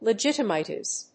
音節le・git・i・ma・tize 発音記号・読み方
/lɪdʒíṭəmətὰɪz(米国英語)/